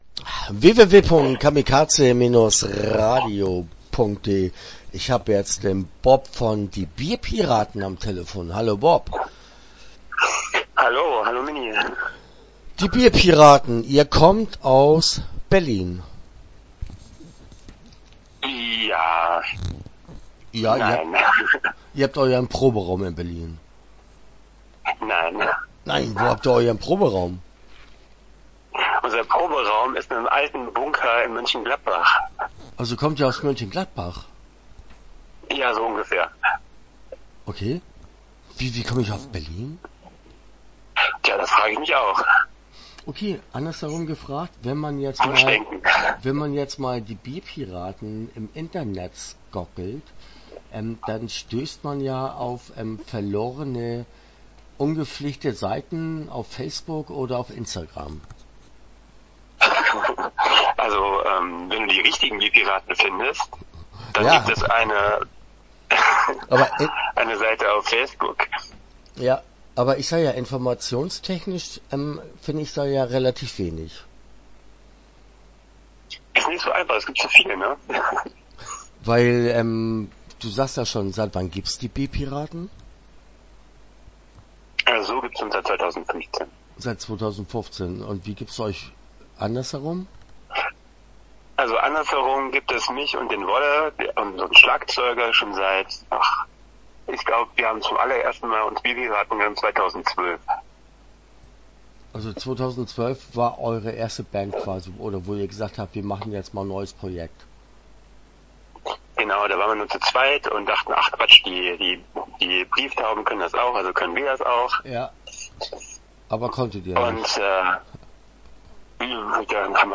Die Bierpiraten - Interview Teil 1 (13:07)